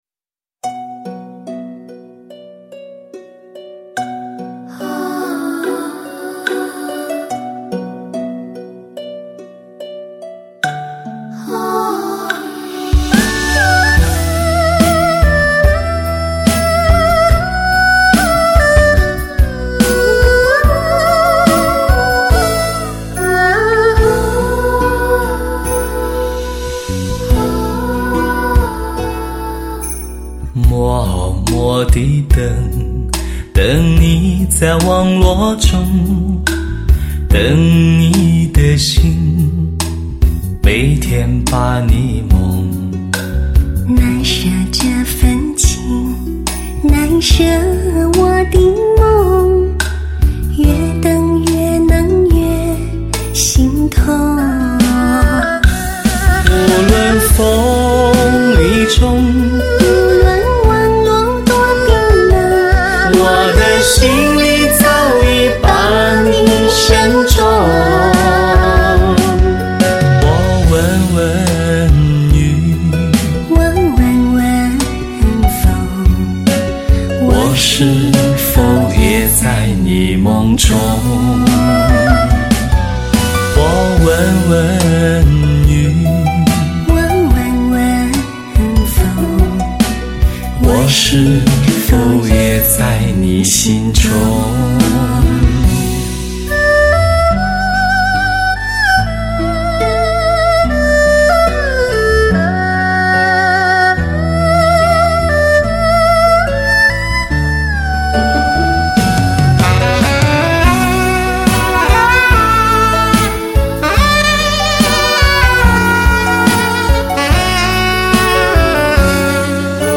唯美浪漫的发烧靓声对唱情歌
发烧靓声对唱极品，情歌精品可遇不可求。